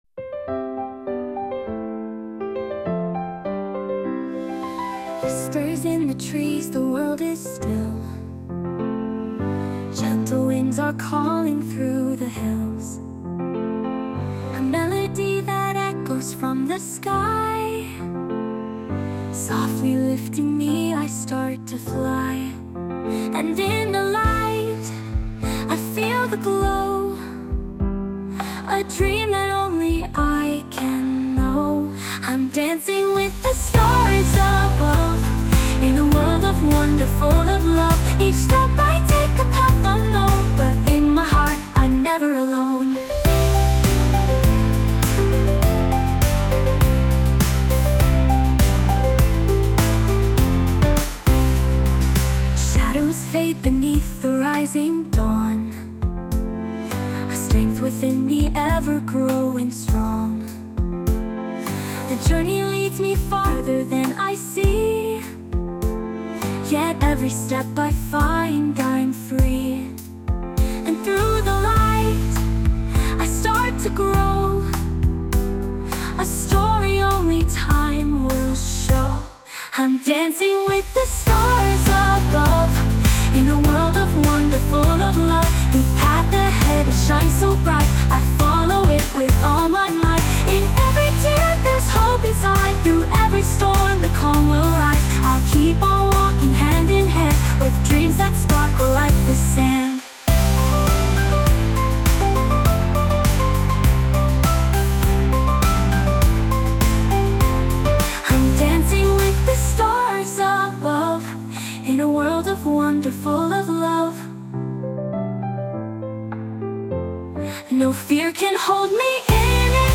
著作権フリーオリジナルBGMです。
女性ボーカル（洋楽・英語）曲です。
ある有名な、○゛ィズニー映画にもなっている、おとぎ話風で、ミュージカルっぽい音楽をイメージしました。